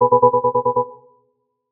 ringtone.272e92fd.mp3